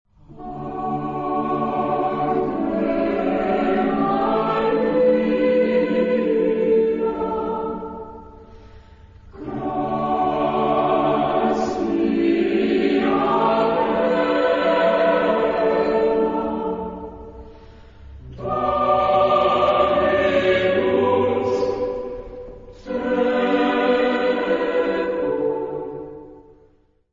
Genre-Style-Form: Sacred ; Motet
Mood of the piece: expressive
Type of Choir: SATB  (4 mixed voices )
Instruments: Piano (1)
Tonality: D major
sung by The Oxford Choir conducted by Bob Chilcott